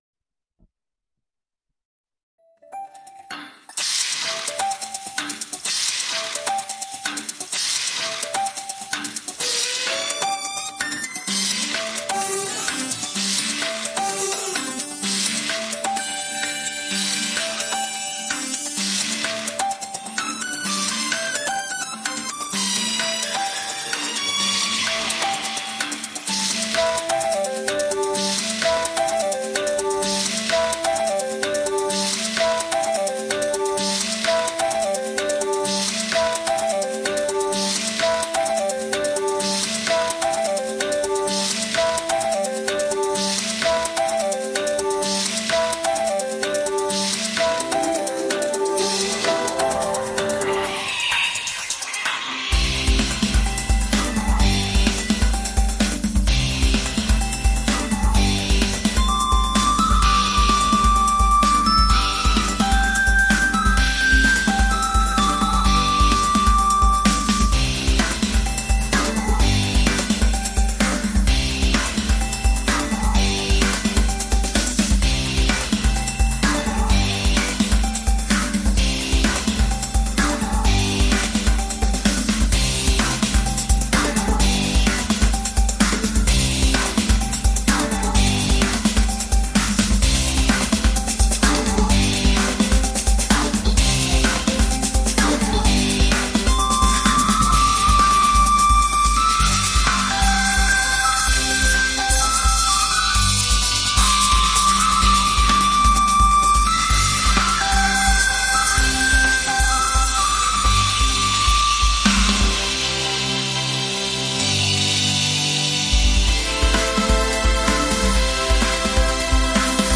・アウトロは、再びエネルギッシュなテンポに戻り、ショッピングモールでの活気ある雰囲気を保ちながらフェードアウトします。